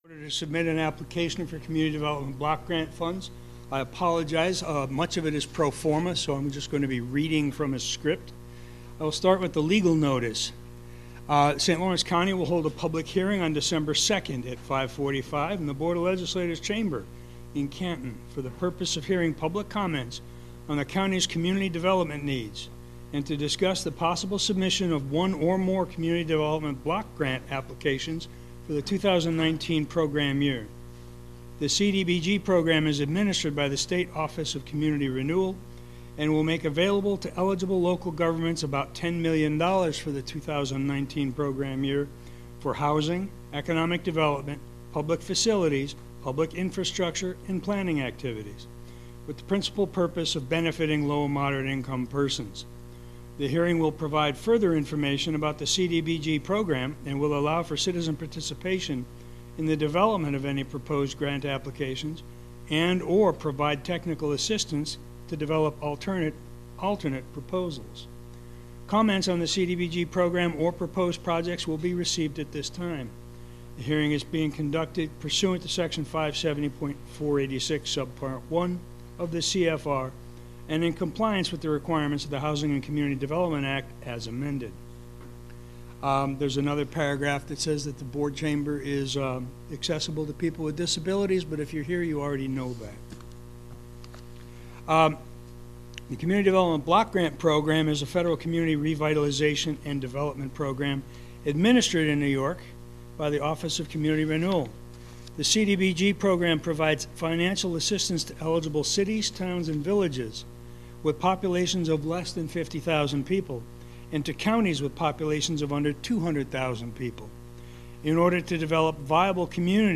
The folder browser user interface will not work properly without it. 12.02.2019 Full Board Recording.mp3 2019 Board of Legislators Meeting Minutes Public 12.02.2019 Full Board Recording.mp3